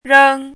chinese-voice - 汉字语音库
reng1.mp3